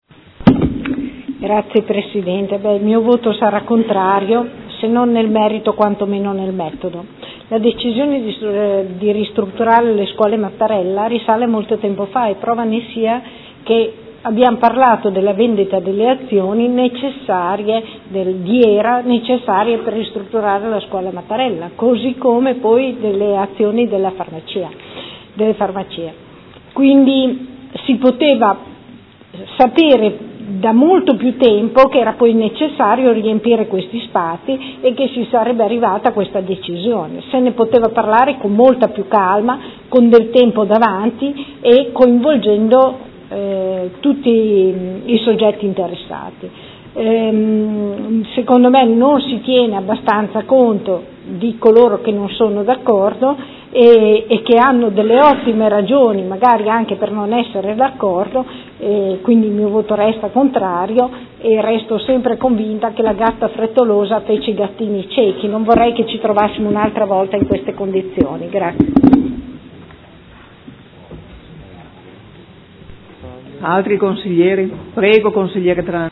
Luigia Santoro — Sito Audio Consiglio Comunale
Seduta del 26/11/2015 Dichiarazione di voto. Delibera: Riorganizzazione della rete scolastica e costituzione degli Istituti Comprensivi